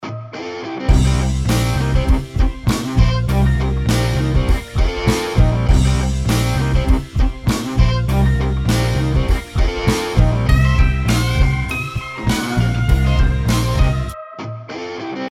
Rock-Klingelton